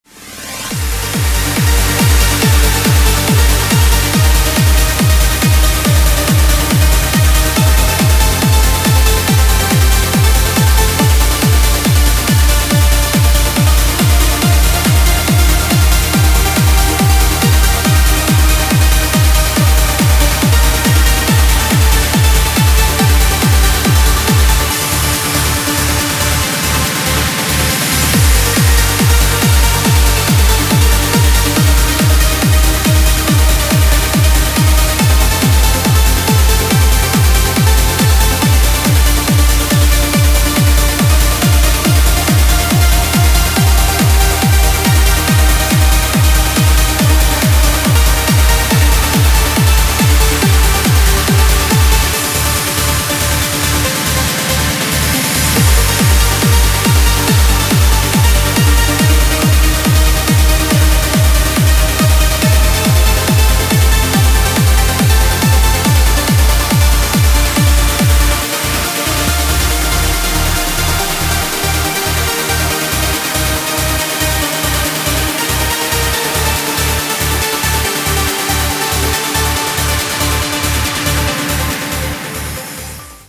• Качество: 320, Stereo
громкие
Electronic
EDM
Trance
Uplifting trance
Красивый uplifting trance